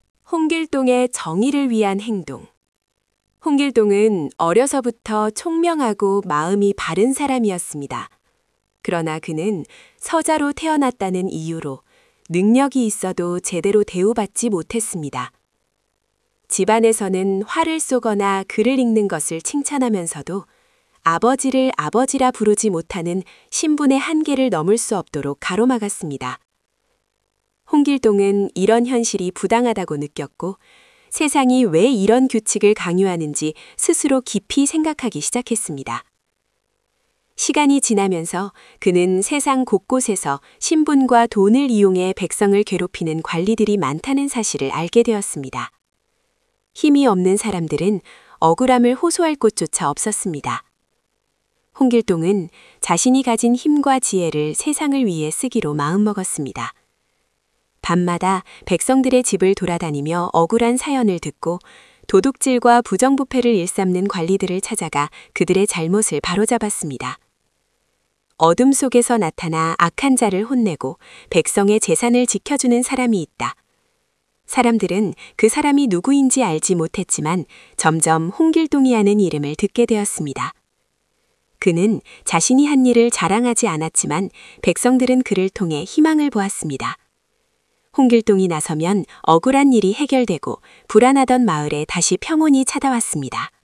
―課題文音声（発音参考用）―